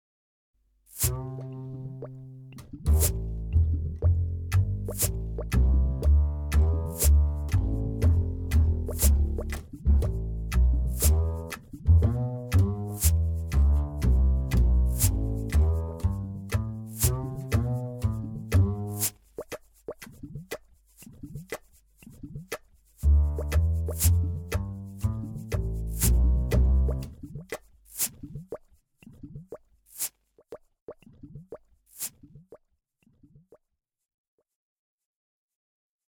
Þetta fyrsta tóndæmi er kannski ekki ýkja merkilegt en þó, heilar 36 sekúndur af bassa og ryþma er ekki afleitt eftir að hafa fiktað í tæpa þrjá tíma við forritið.
Hjartað í þessu tóndæmi er auðvitað bassalínan sem ég hef ekkert breytt. Það sem hljómar kannski eins og trommusláttur með burstum er hvissið af bjórflösku sem er opnuð og klapp-takturinn eru fingrasmellir með "chorus-effect".
Loftbóluhljóðin voru tekin upp meðan á gerjuninni stóð, svona hljómar open source bruggun!